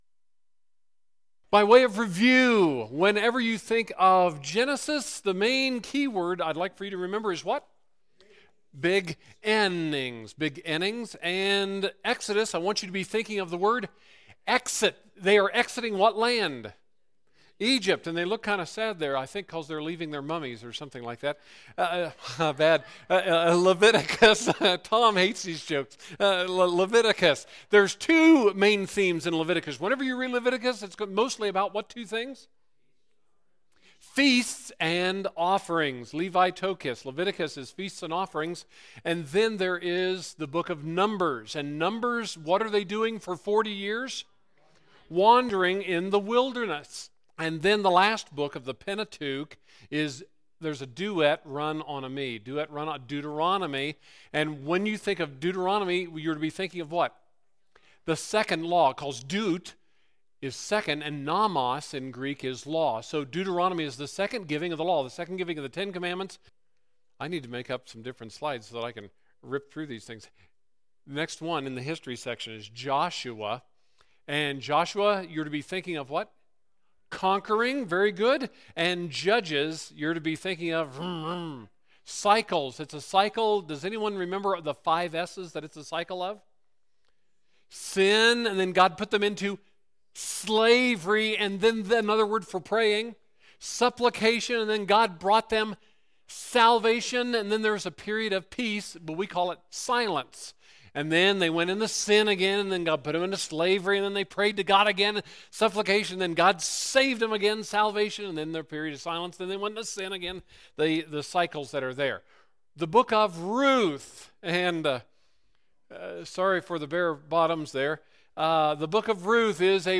Sunday School…